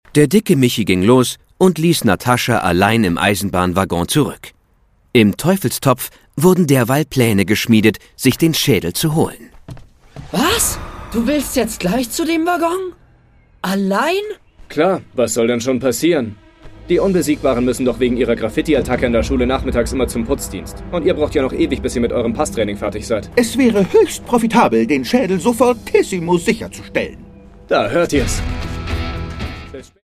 Produkttyp: Hörspiel-Download
Fassung: ungekürzt